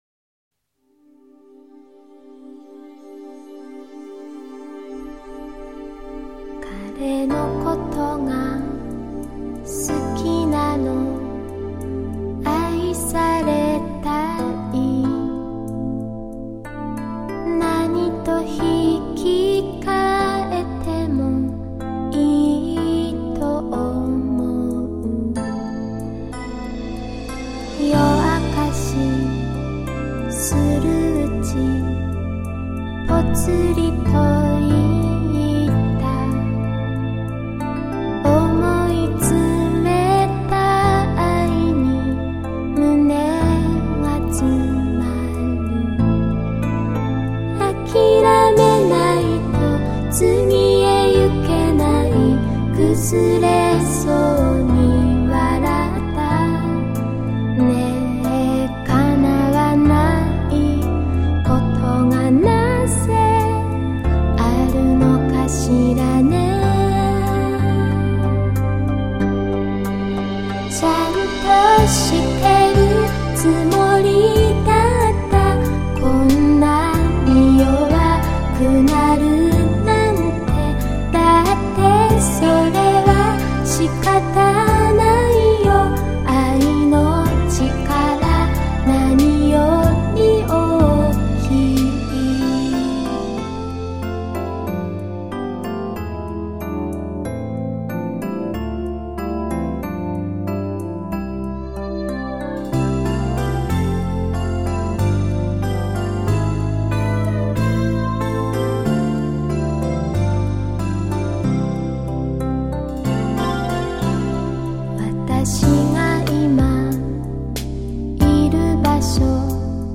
她注重旋律的悠扬，喜欢用简单而朴实的伴奏衬托出优美的旋律，同时也致力于营造出一个场景、一种气氛。